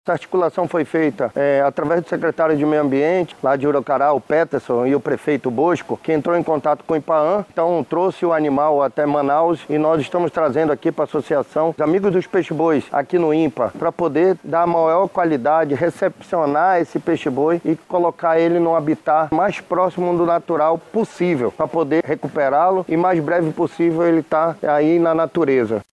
O diretor-presidente do Ipaam, Gustavo Picanço, explica que o processo de reabilitação tem como foco a possibilidade de reintrodução do animal na natureza.